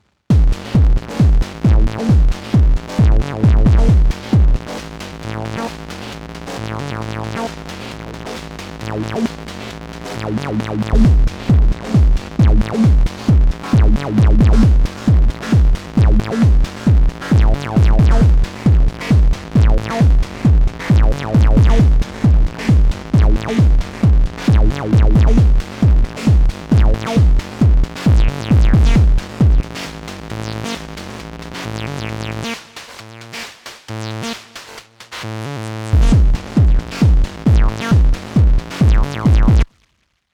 I‘m actually on the silly quest to make a patch that enables „modular techno impro“ on just a Hydrasynth Desktop.
OSC 1 = noises and bass, OSC 2 = bassline/lead, OSC 3 = kick.
In all cases, one latched note (no arp, no sequencer).